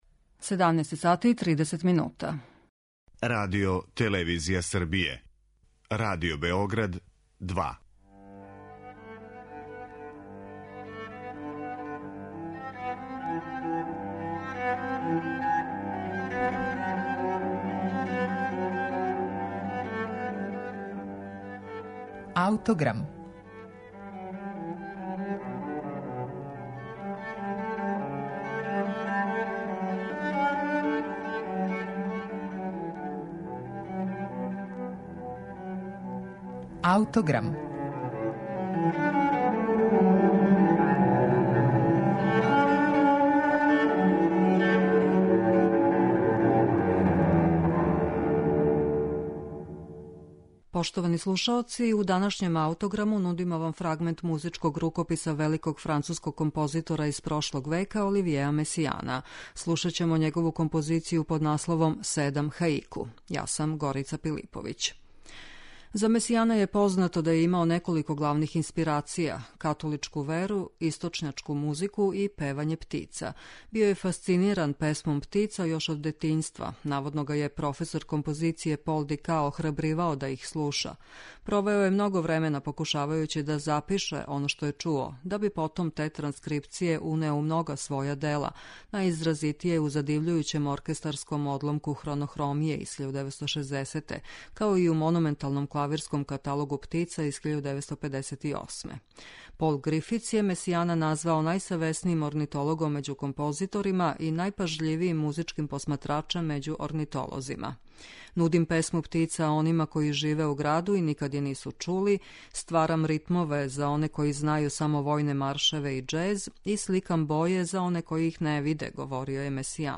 у којој је такође употребио песму птица.